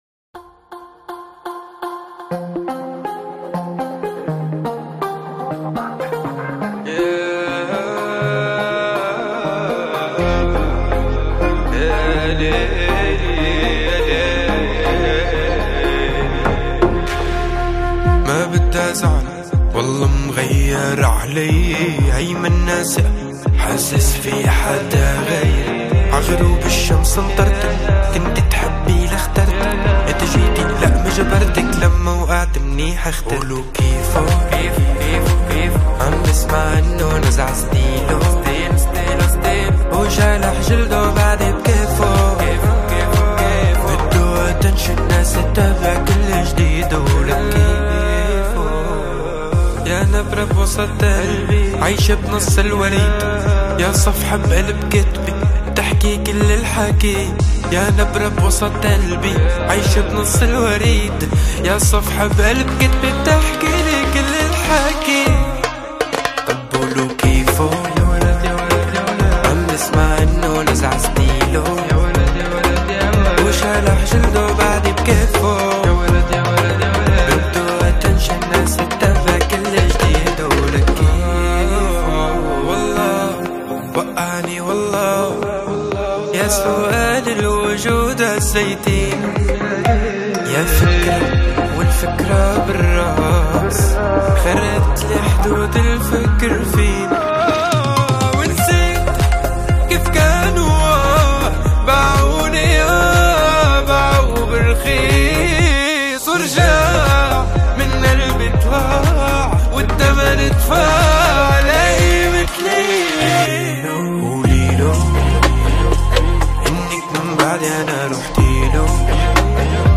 • النمط الموسيقي: بوب بديل / ريب شرقي.